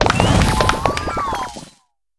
Cri de Yuyu dans Pokémon HOME .